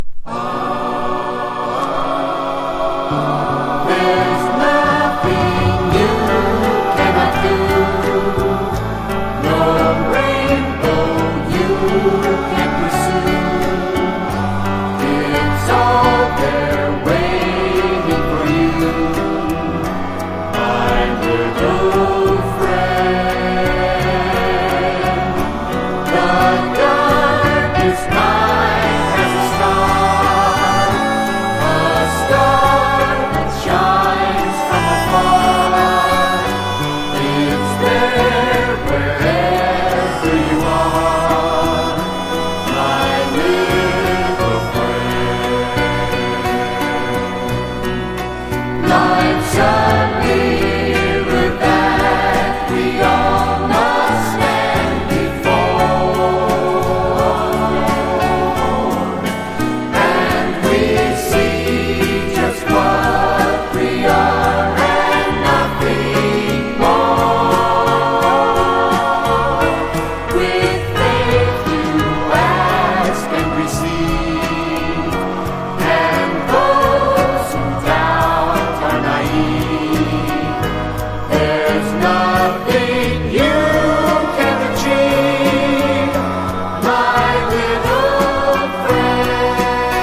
ソフト・ロック的コーラスも素晴らしいです。
SOFT ROCK
• 盤面 : EX+ (美品) キズやダメージが無く音質も良好